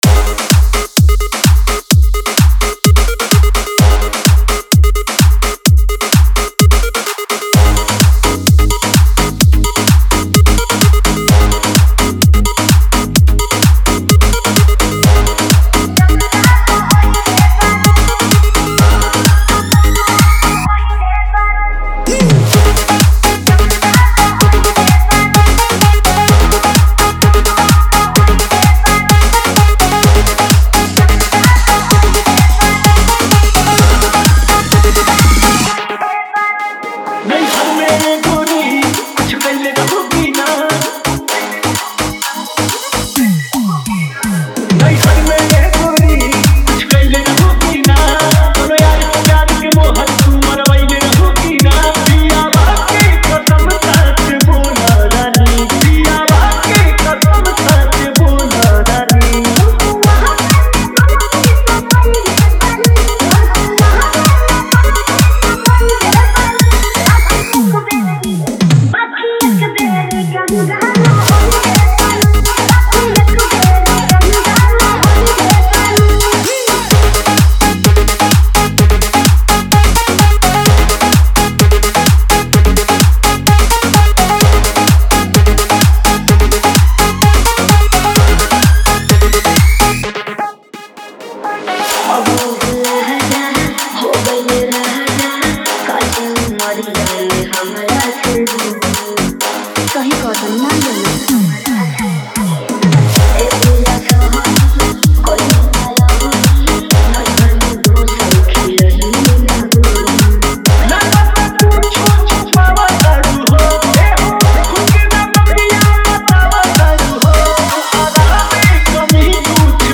Category : Bhojpuri DJ Remix Songs